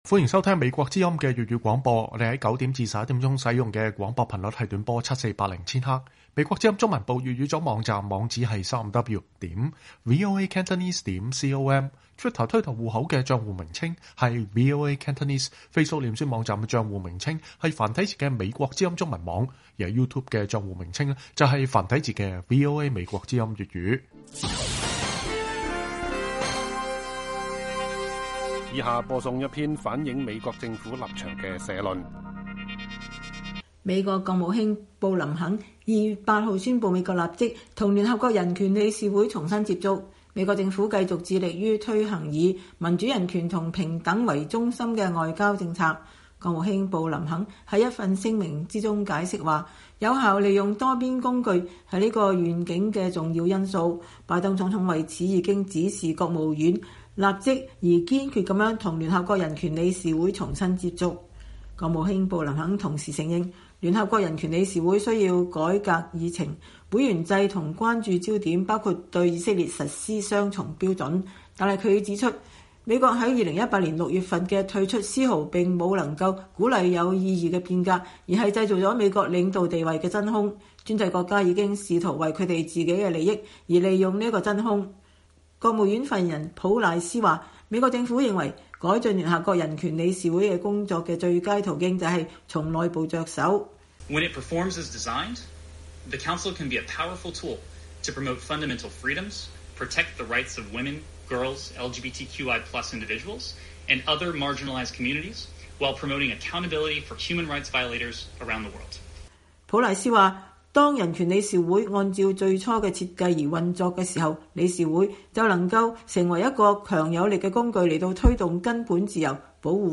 美國政府立場社論